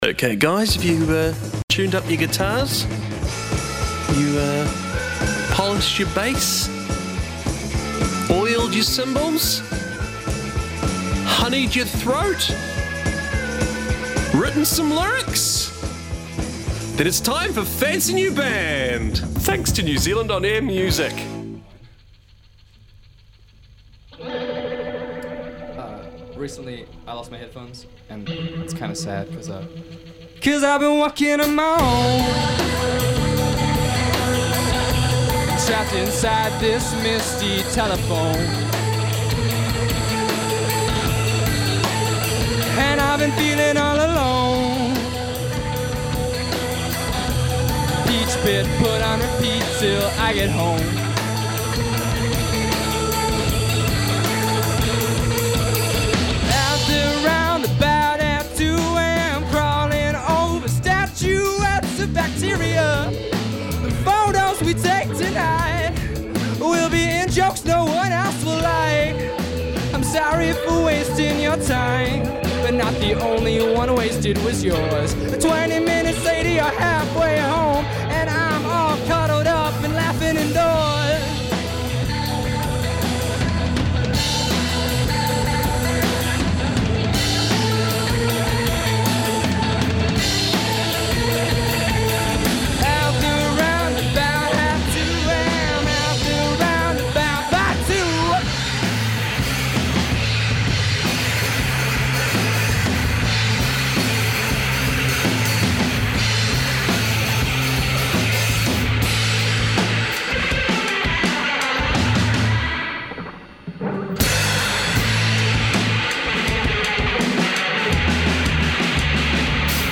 a rapper and producer based in West Auckland.